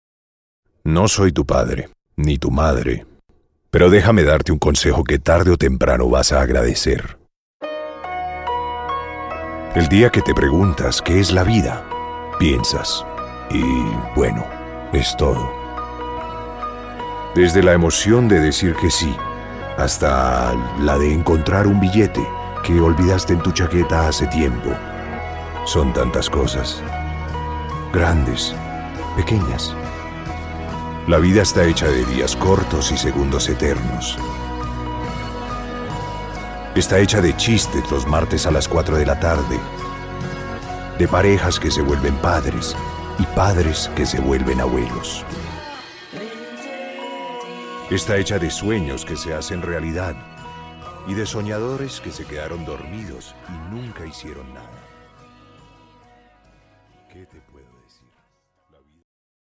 Deep and sensual bass baritone voice. Voix grave, profonde, sensuelle.
spanisch Südamerika
Sprechprobe: Werbung (Muttersprache):